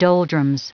Prononciation du mot doldrums en anglais (fichier audio)
Prononciation du mot : doldrums